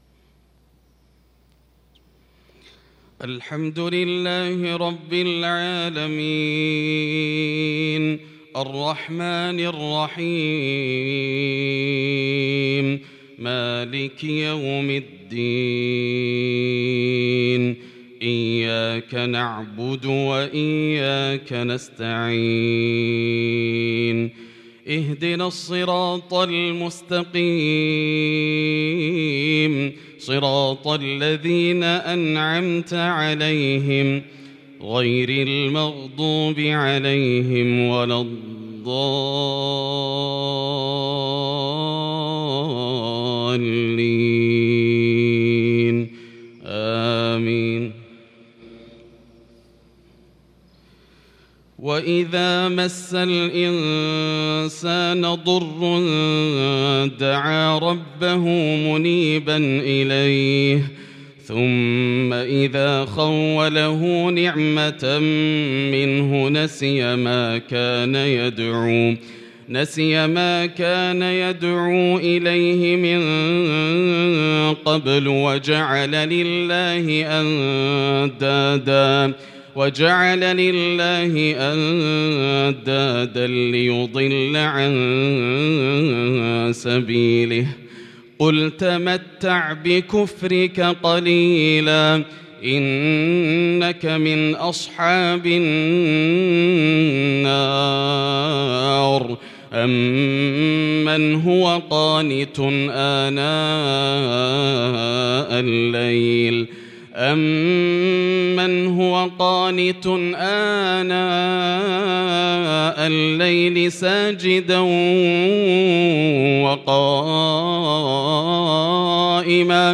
صلاة الفجر للقارئ خالد المهنا 22 ربيع الأول 1443 هـ
تِلَاوَات الْحَرَمَيْن .